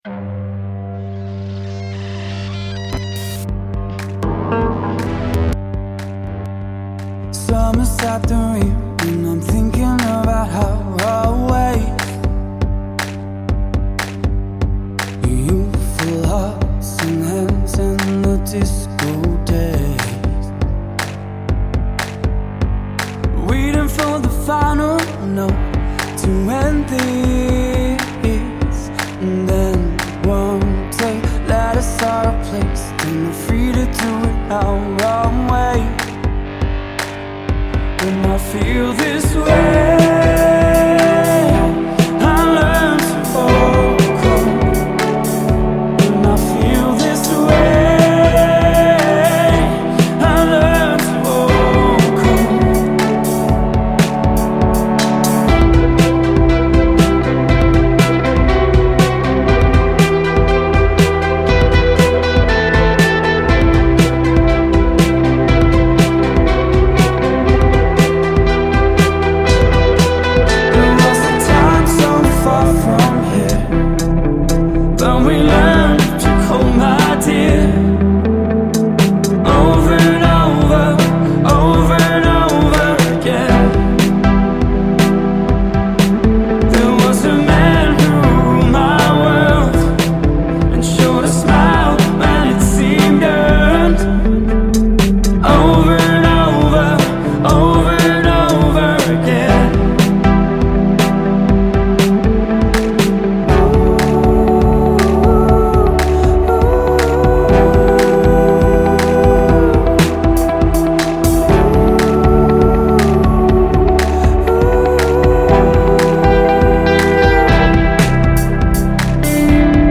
Australian duo
singer/guitarist